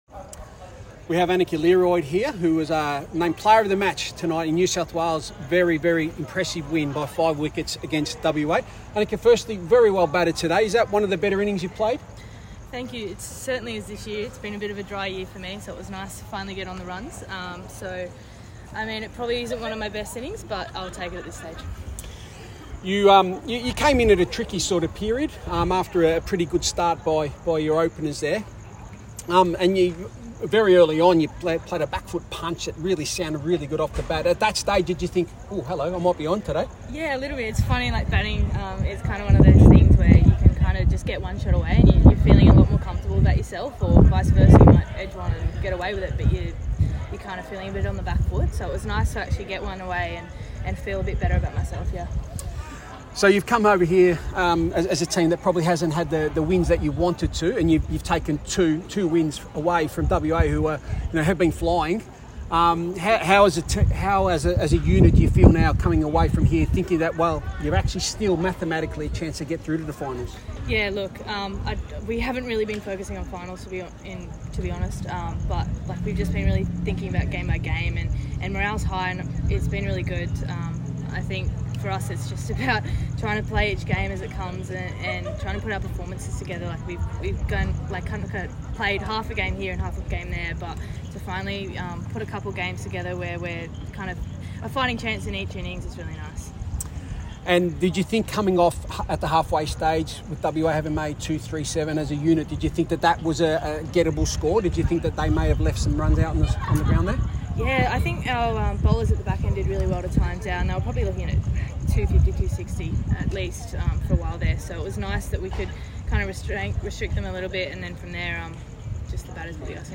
spoke following NSW’s five-wicket victory in the WNCL at the WACA.